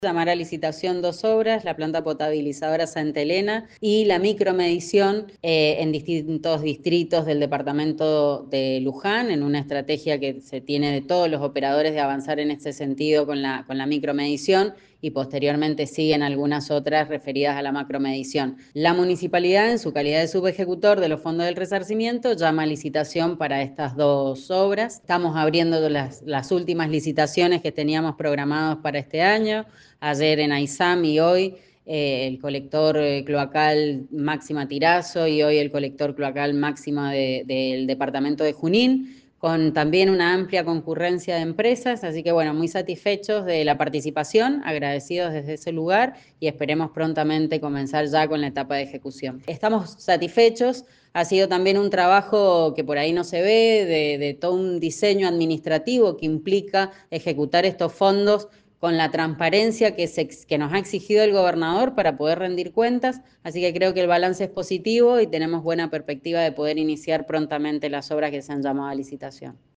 Marité Badui, subsecretaria de Infraestructura y Desarrollo Territorial.